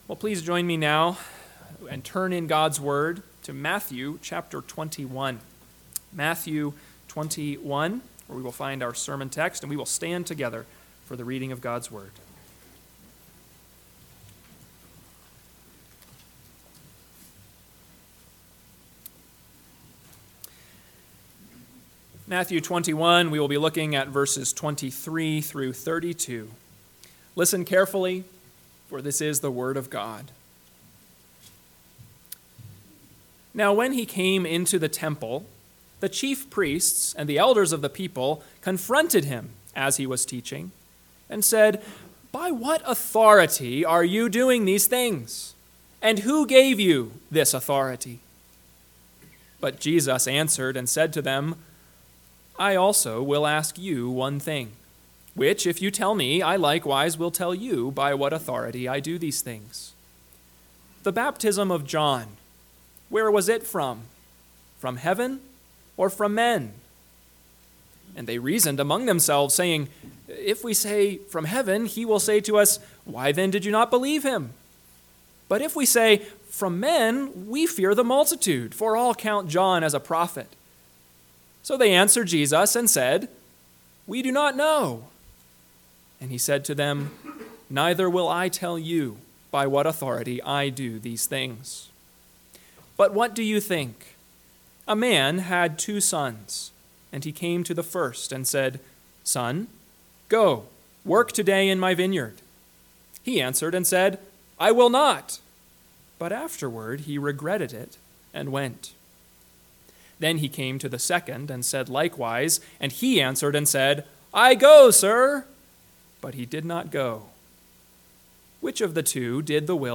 AM Sermon – 9/8/2024 – Matthew 21:23-32 – Northwoods Sermons